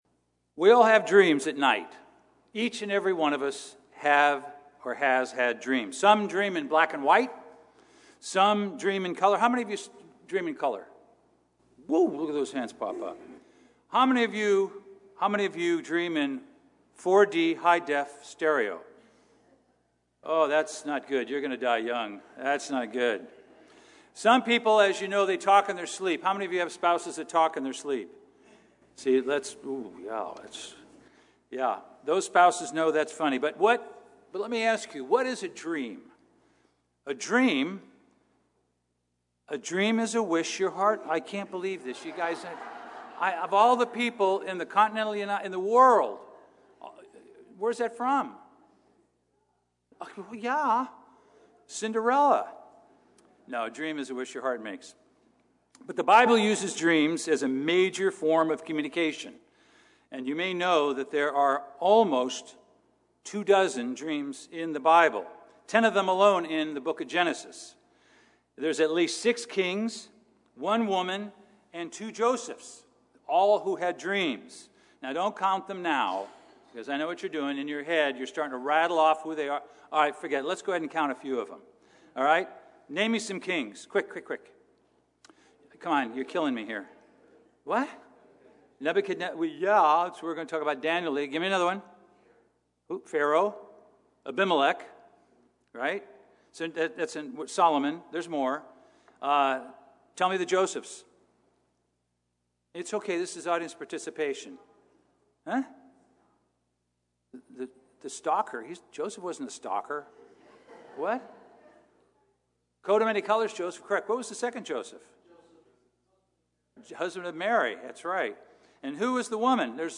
Sermons
Given in Los Angeles, CA